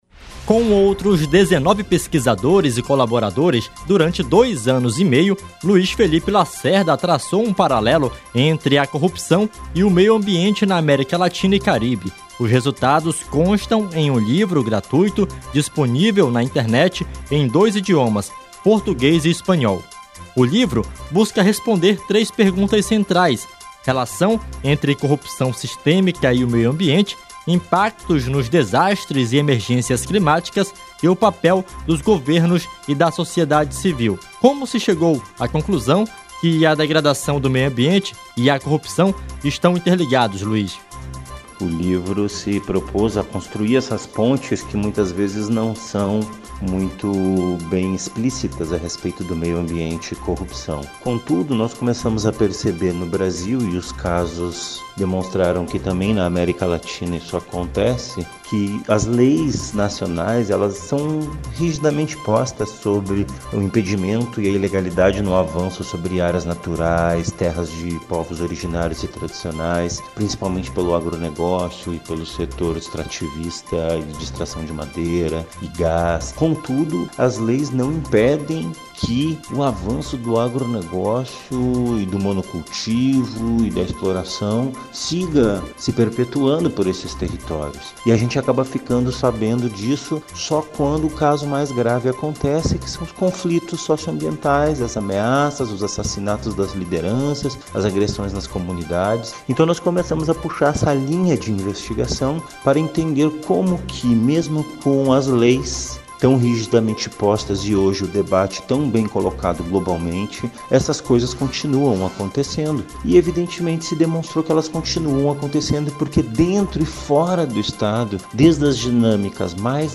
Livro gratuito detalha a relação entre a corrupção e o meio ambiente na América Latina e Caribe. Rádio Rio Mar disponibiliza entrevista